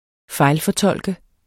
Udtale [ ˈfɑjlfʌˌtʌlˀgə ]